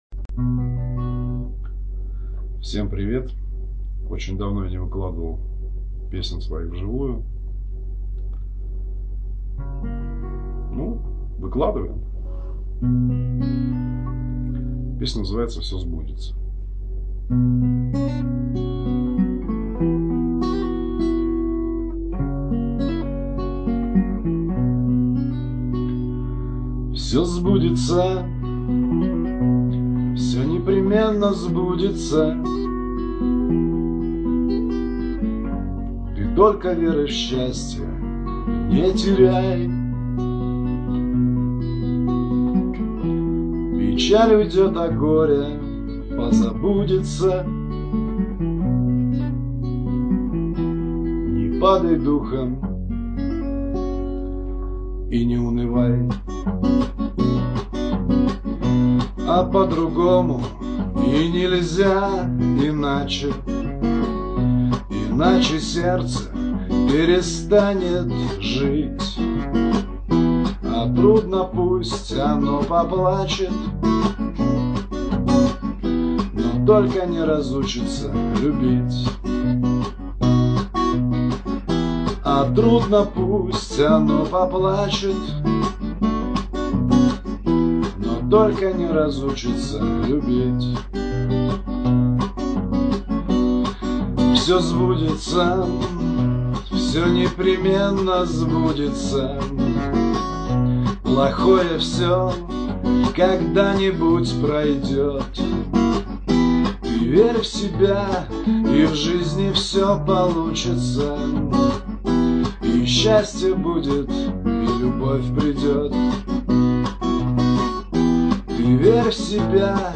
Песня Гитара Стихи